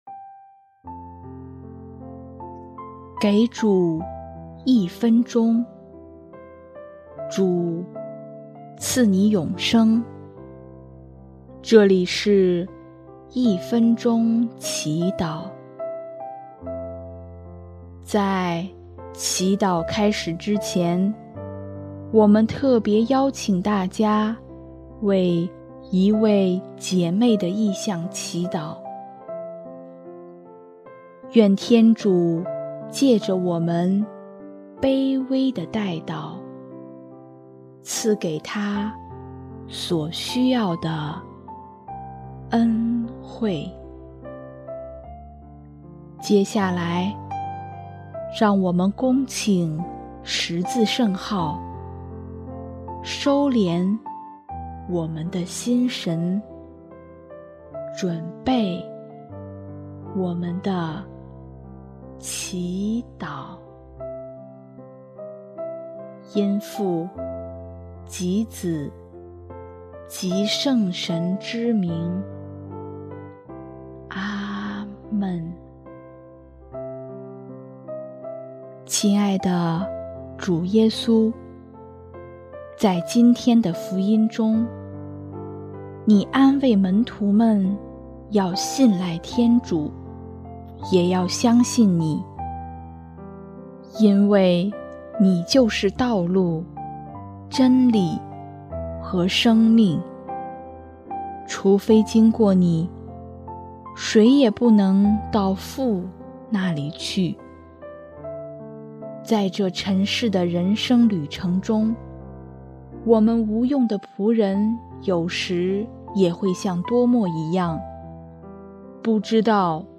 【一分钟祈祷】| 5月7日 以信赖之心活出生命的真谛